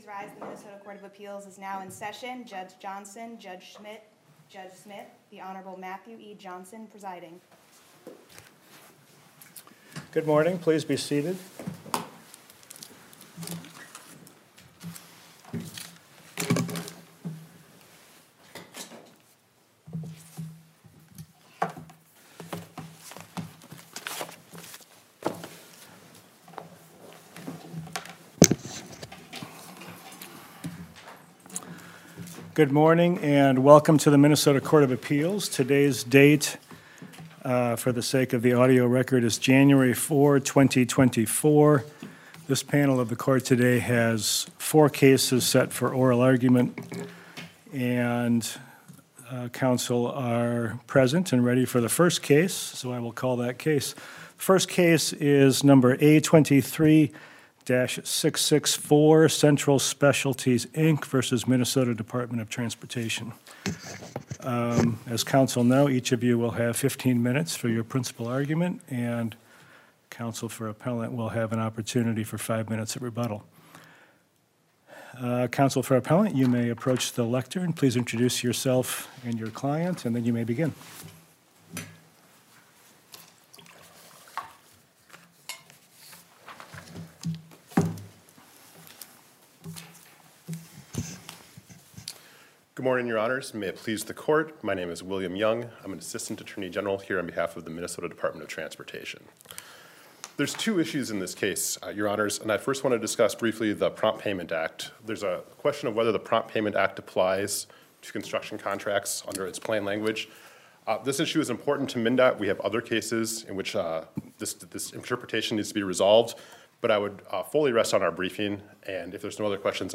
Minnesota Court of Appeals Oral Argument Audio Recording